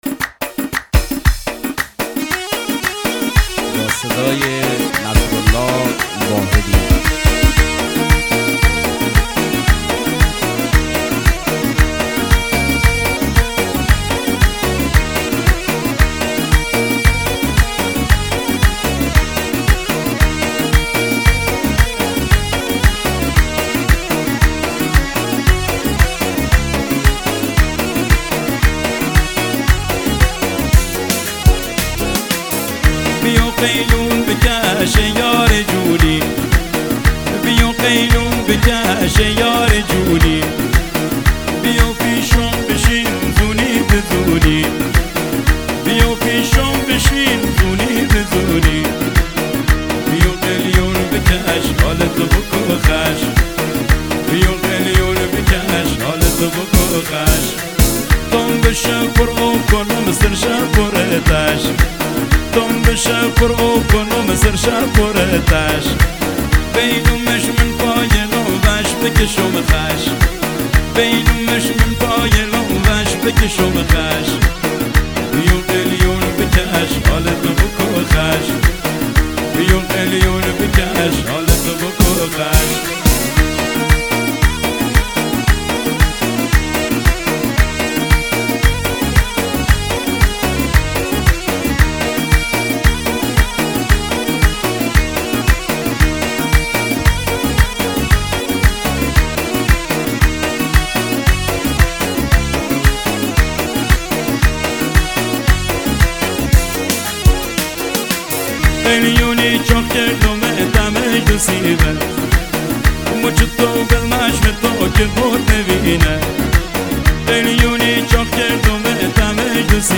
شاد و پرهیجان
آماده‌اید برای یک سفر دلنشین و پرشور به عمق موسیقی محلی؟
با ریتم‌های گیرا